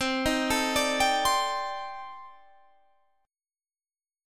Listen to Cm6add9 strummed